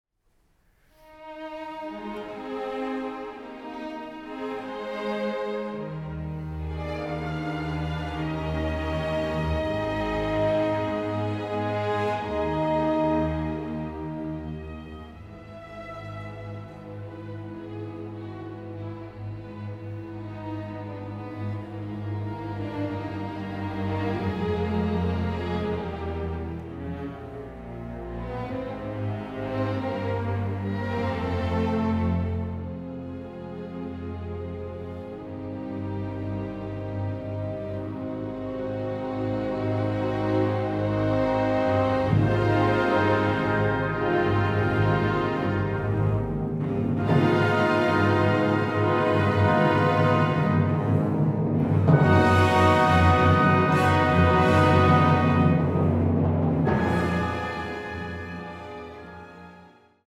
MDR-Sinfonieorchester
Dennis Russel Davies Dirigent
Der Live-Mitschnitt zeigt, auf welchem Niveau der Sinfoniker Bruckner in sein Oeuvre einsteigt und lässt in Details die Charakteristika der reifen Sinfonien aufblitzen.